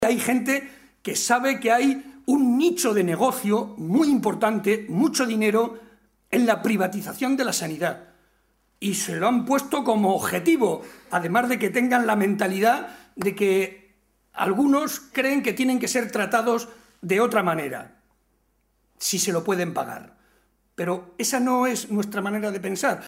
Barreda argumentó que la realidad es más complicada que las “falsas expectativas” que lanzan los responsables políticos del PP, y para comprobarlo, señaló que tan sólo hay que ver los casos de Portugal, Castilla-La Mancha o Villarta de San Juan, localidad en la que intervino en un acto público, donde se han producido esos relevos de Gobierno y “las cosas, lejos de mejorar, han ido a peo”, demostrando que la realidad es mucho más tozuda y no van a conseguir, ni mucho menos, mejorar la situación”.